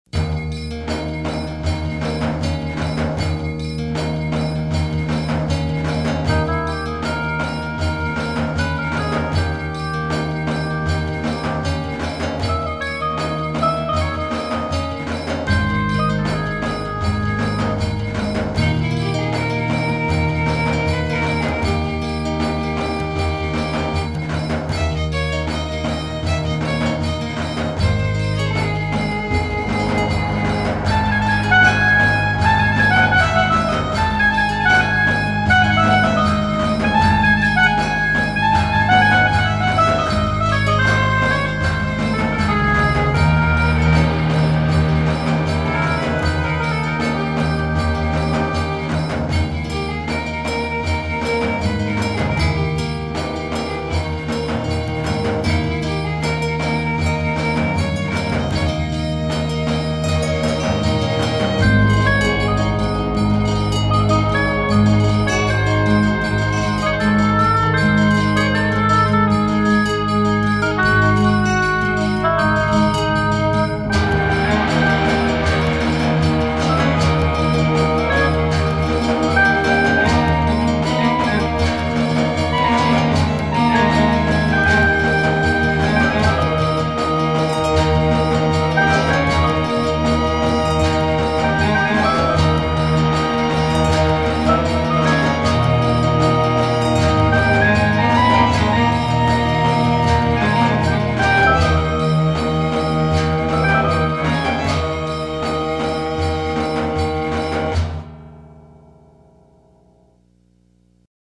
В наше время создается огромное количество псевдо-египетской музыки.